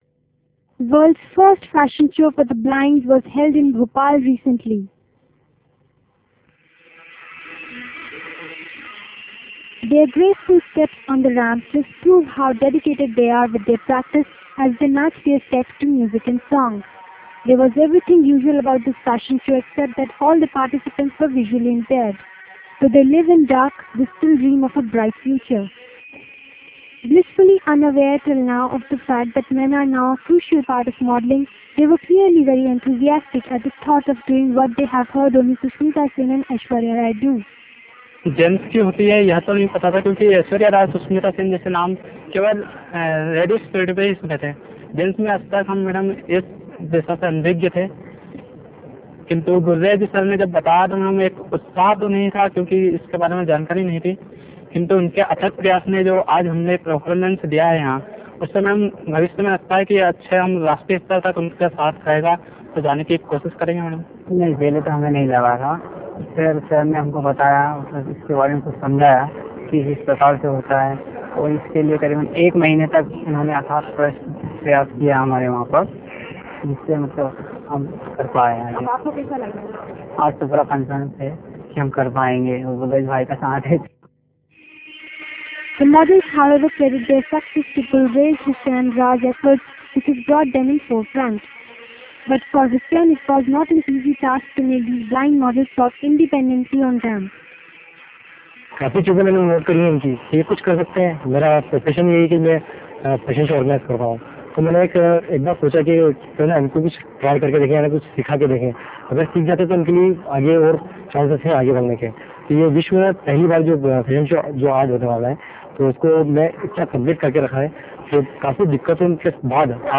World's first fashion show for the blind was held in Bhopal recently.
Their graceful steps on the ramp prove how dedicated they are in their practice as they step to the beat.